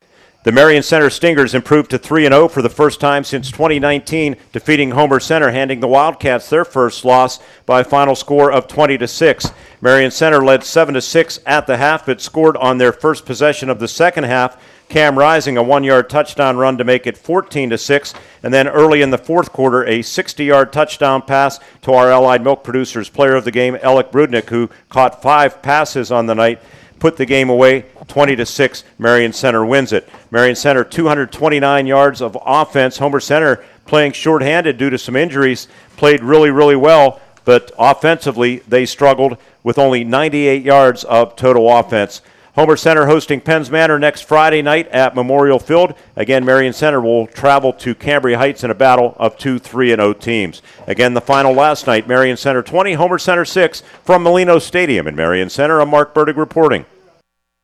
9-5-25-hc-at-mc-recap.mp3